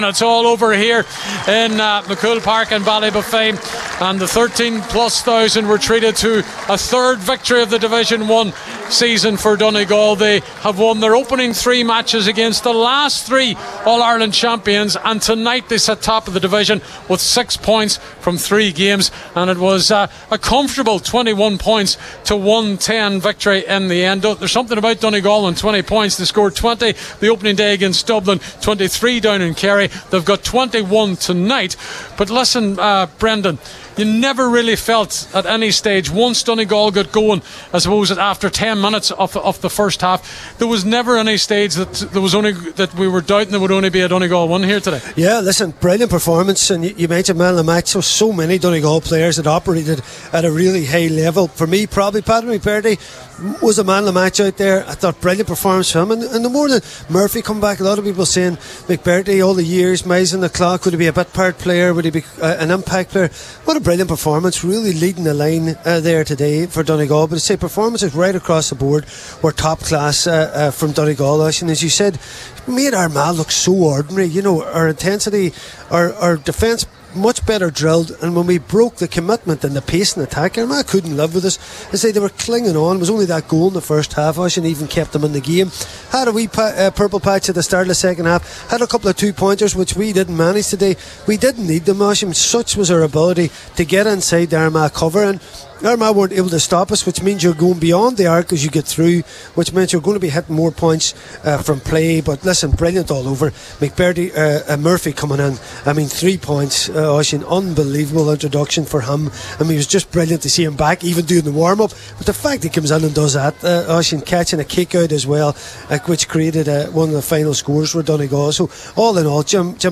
post-match reaction